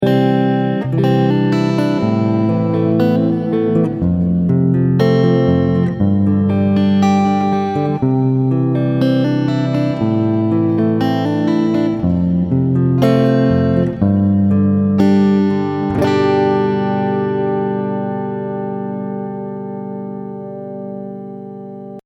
In the first example, I play a chord progression in C major. However, I do not end it with the C major chord.
C, Am, F, G
Sounds kind of unfinished, doesn’t it?